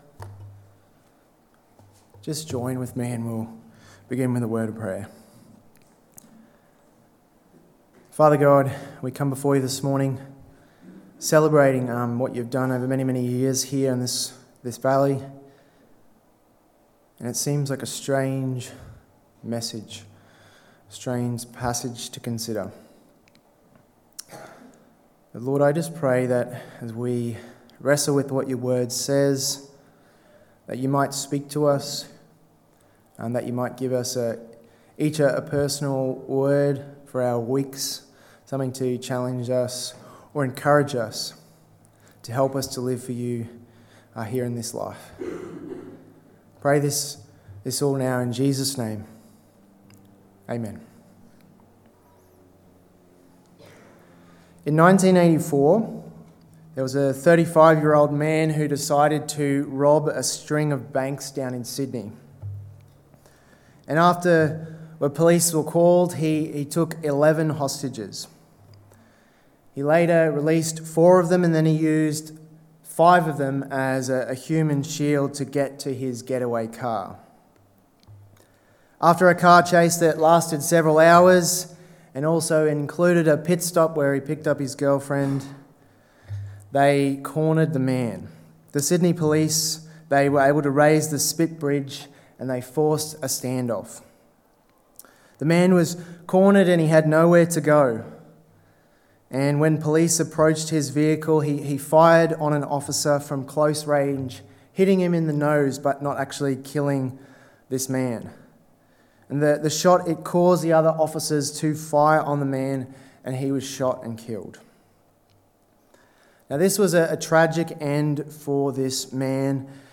Sermons | Tenthill Baptist Church